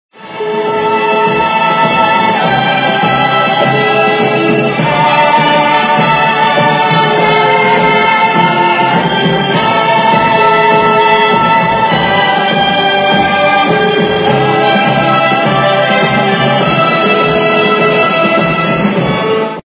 Из фильмов и телепередач